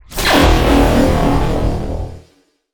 arrive_in_level_2.wav